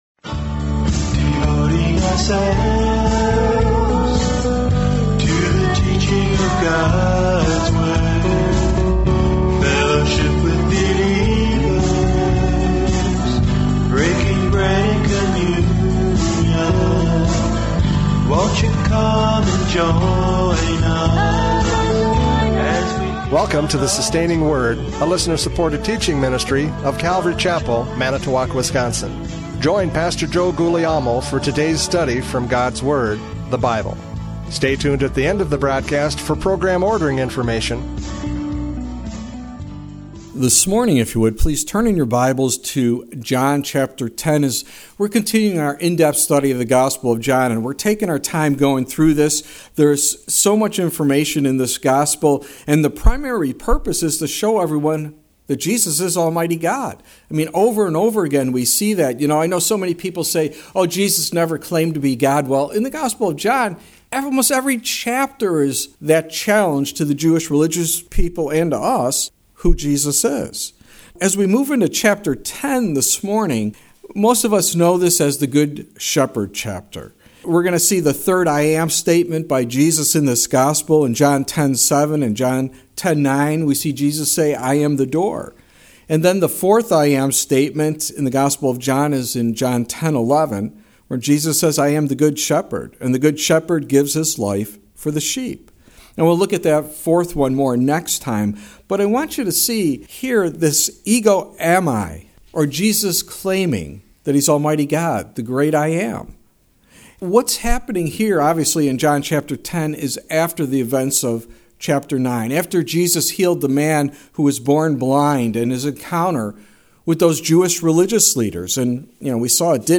John 10:1-10 Service Type: Radio Programs « John 9:35-41 Spiritual Blindness!